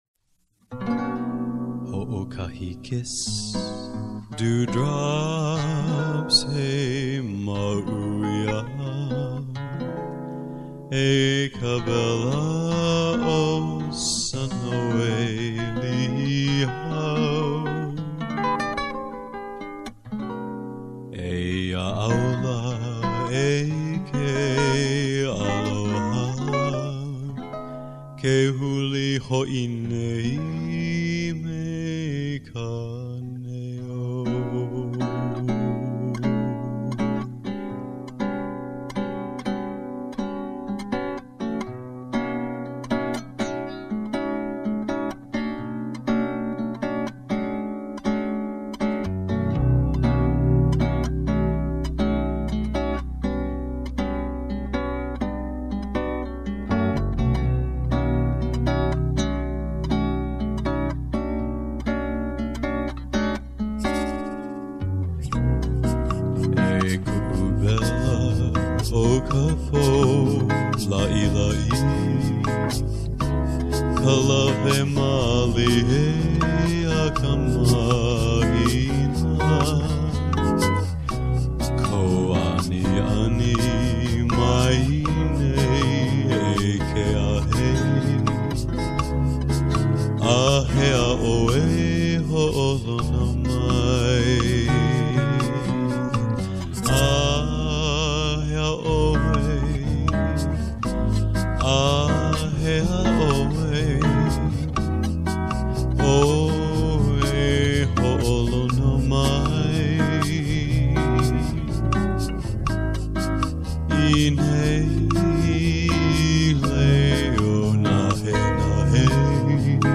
I chose Brazil.
You can really croon!
I like the Brazilian rendition.
The rythyms are great. A velvet voice, so versatile.